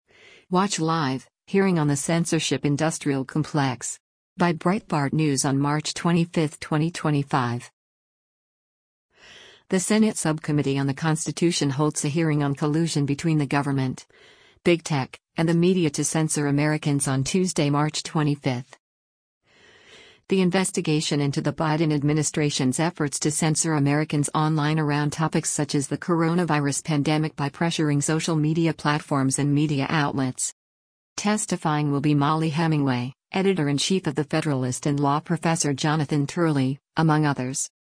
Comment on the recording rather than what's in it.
The Senate Subcommittee on the Constitution holds a hearing on collusion between the government, Big Tech, and the media to censor Americans on Tuesday, March 25.